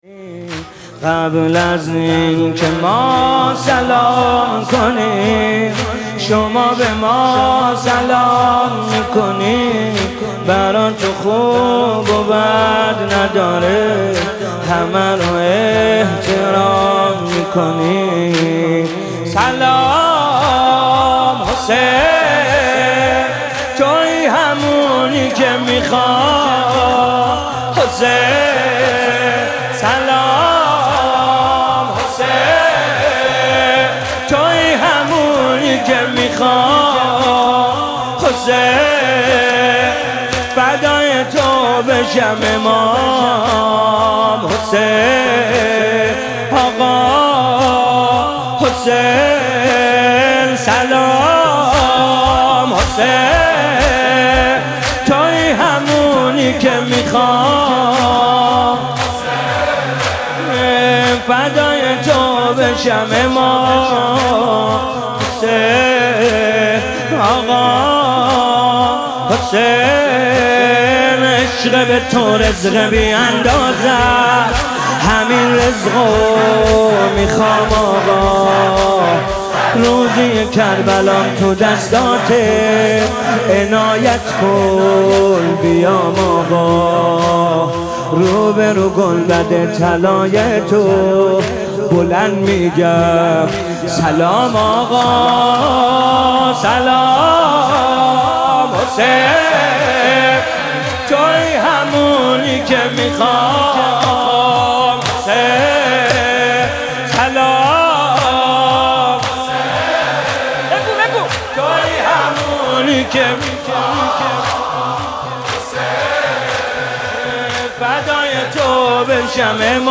مراسم عزاداری هیأت فدائیان حضرت زهرا(س) در دهه دوم ماه محرم
مداحی